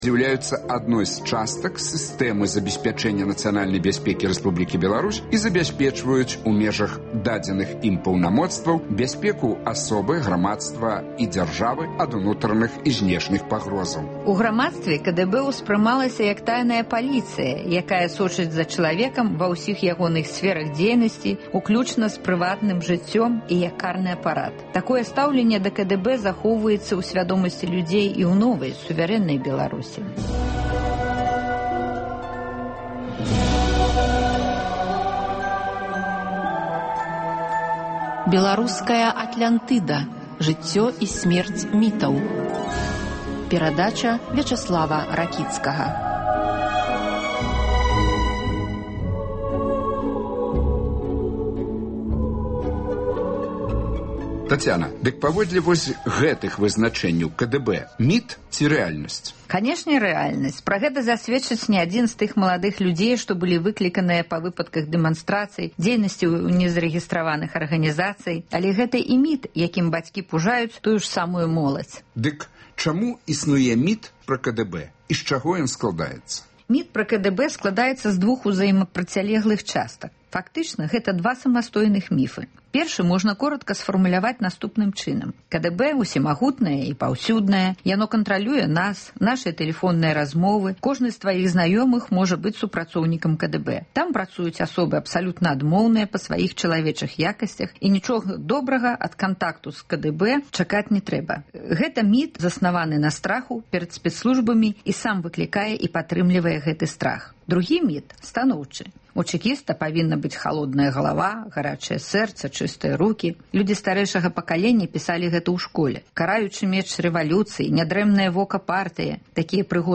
гутарыць зь філёзафам